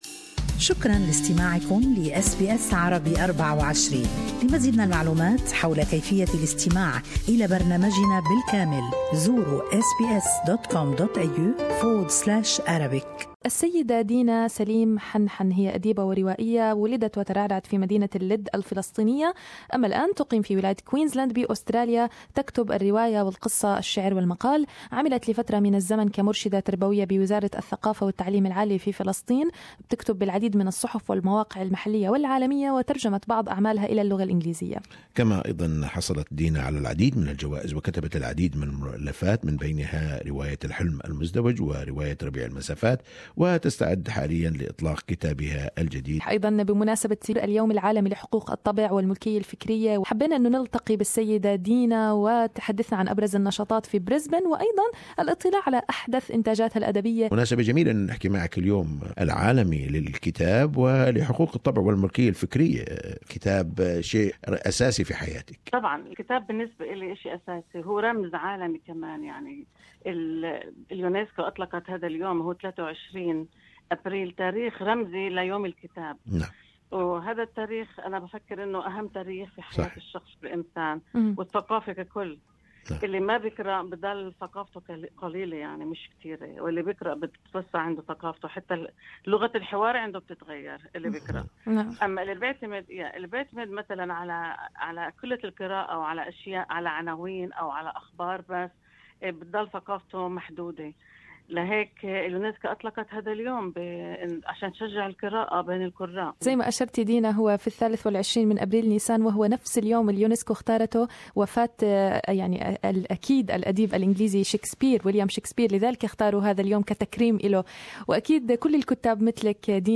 World Book and Copyright Day is an opportunity to highlight the power of books to promote our vision of knowledge societies that are inclusive, pluralistic, equitable, open and participatory for all citizens. Interview